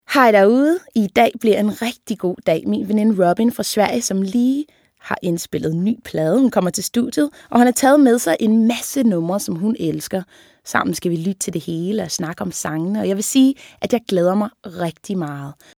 » Stemmeprøver